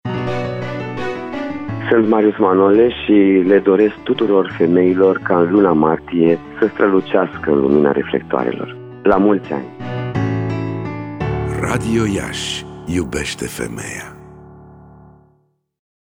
De astăzi, timp de o săptămână, pe frecvenţele de 1053 Khz, 90,8, 94,5 şi 96,3 Mghz, vor fi difuzate următoarele spoturi:
Actorul Marius Manole